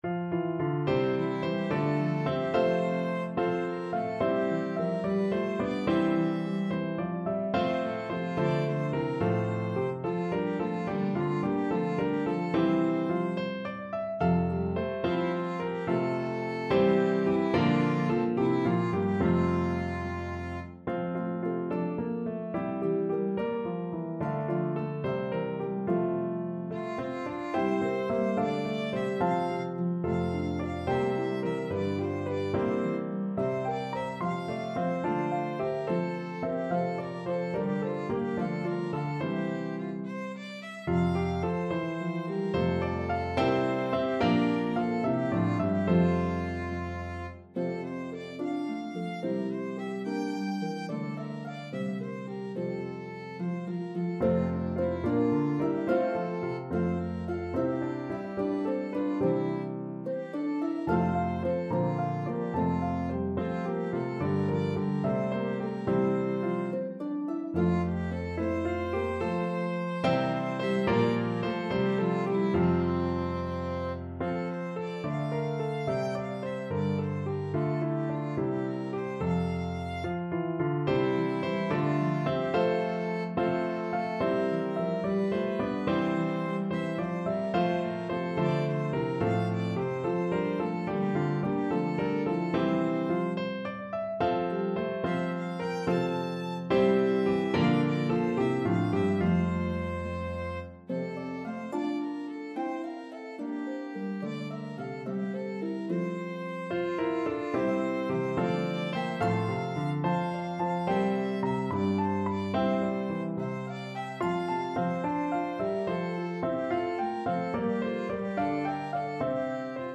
It is now both an Advent Hymn and Christmas Carol.